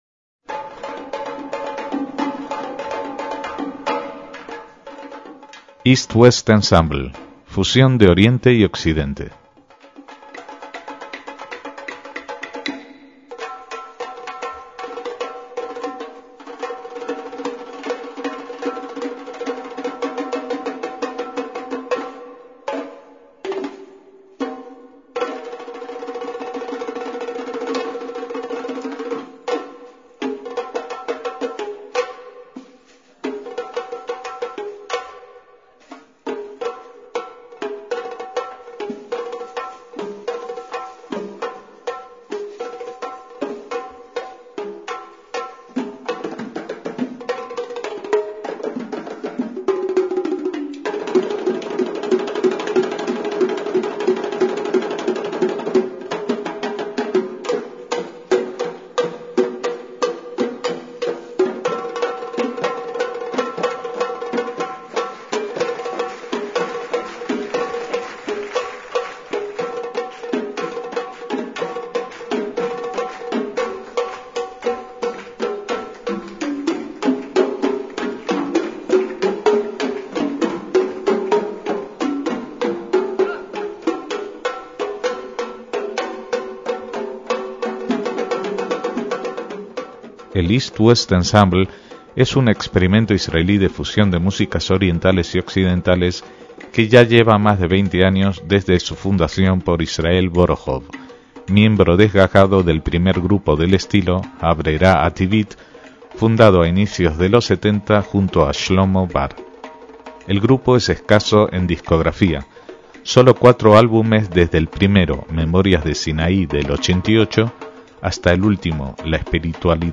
chelo
flauta, clarinete, saxofón, zurna